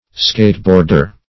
skateboarder - definition of skateboarder - synonyms, pronunciation, spelling from Free Dictionary